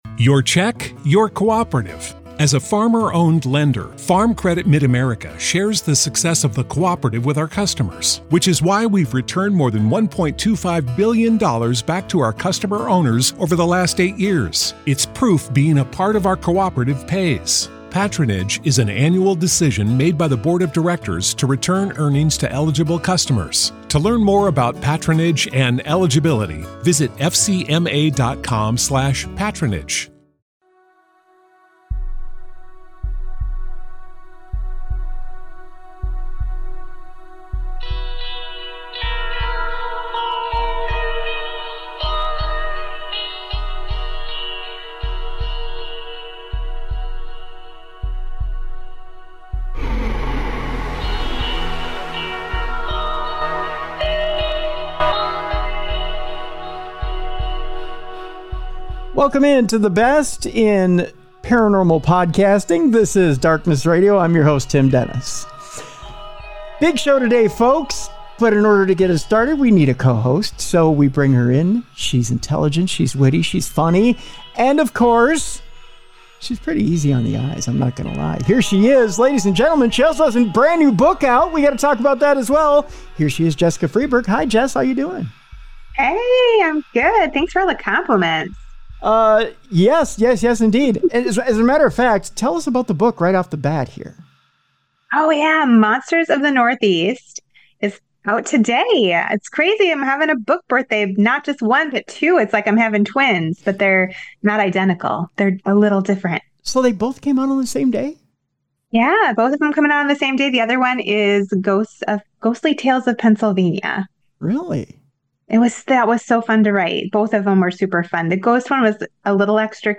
This Week, While one ship in space is making funny noises, NASA is giving us the sound of space, and we'll play it for you on today's show!! A Ghost Hunter is horrified after a haunted church wooden door opens by itself! And, According to the NSA, Russia had weapons related to Havana Syndrome!